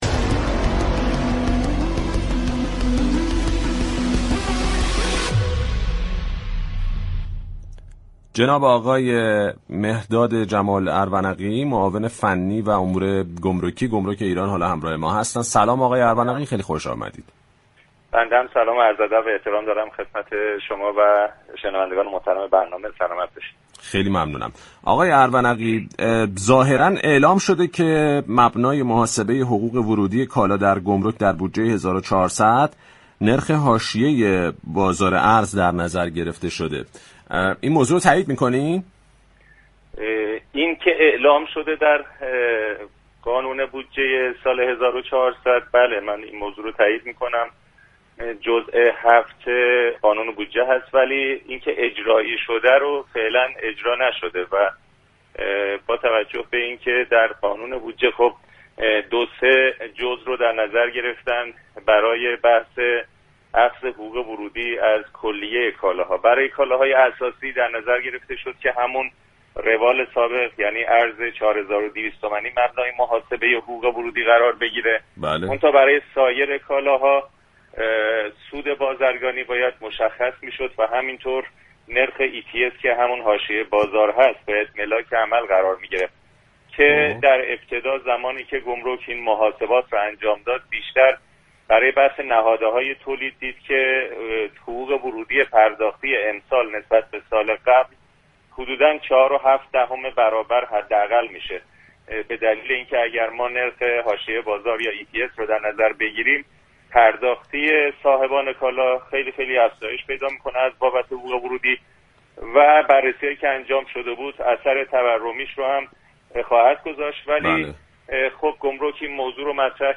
مهرداد جمال ارونقی معاون فنی گمرك ایران در گفتگو با بازار تهران